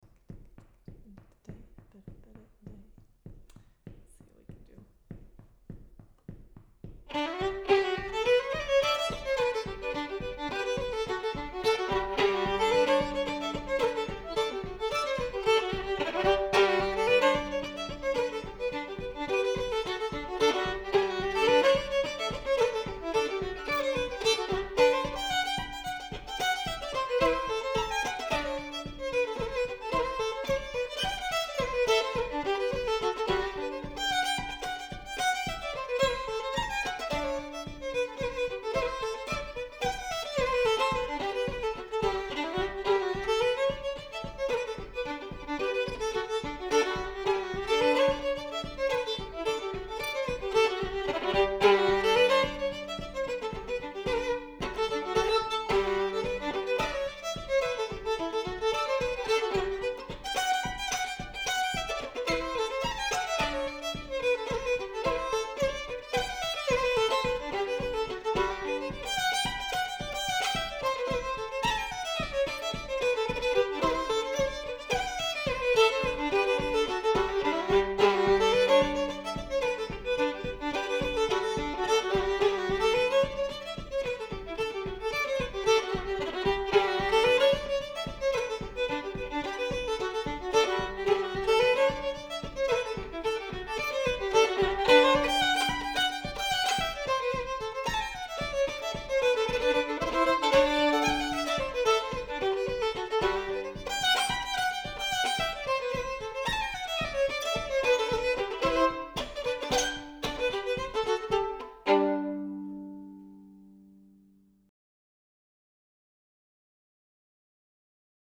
Tom Turkington's in G Major